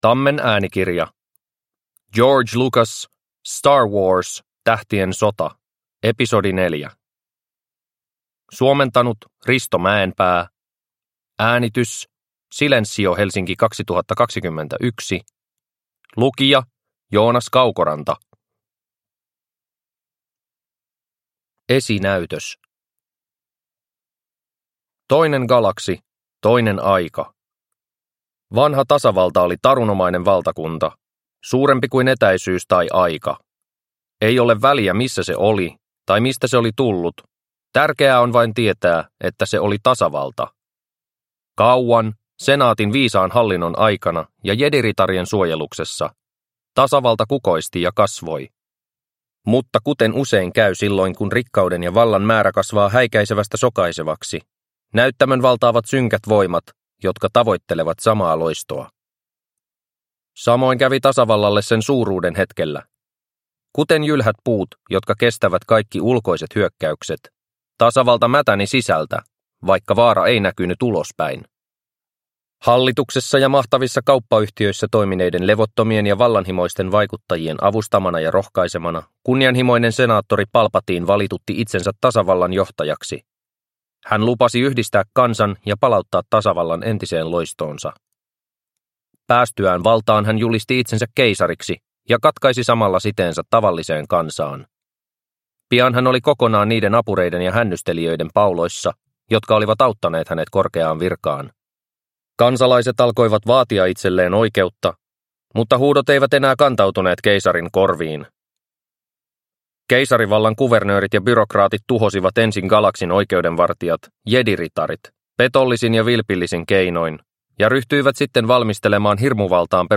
Star Wars. Tähtien sota (ljudbok) av George Lucas